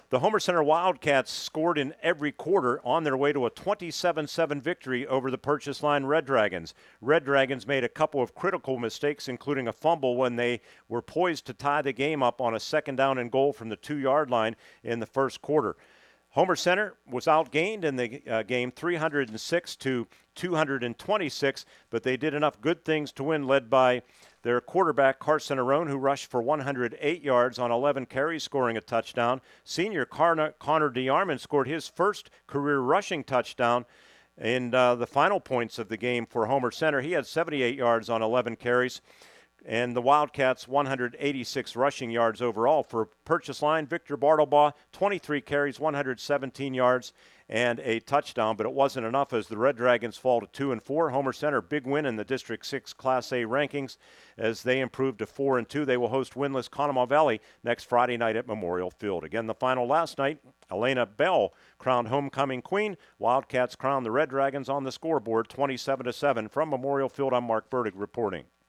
9-26-25-mb-short-wrap.mp3